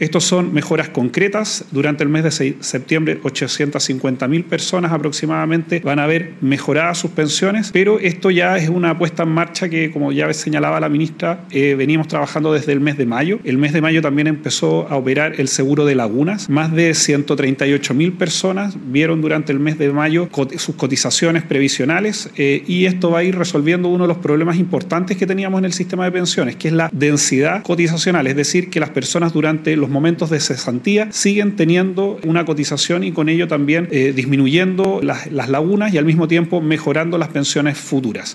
Por su parte, el ministro del Trabajo y Previsión Social, Giorgio Boccardo, recordó que ya está operativo el Seguro de Lagunas Previsionales, implementado en mayo de 2025, que ha permitido que más de 130 mil personas mantengan sus cotizaciones durante periodos de desempleo, evitando así vacíos que impacten sus pensiones futuras.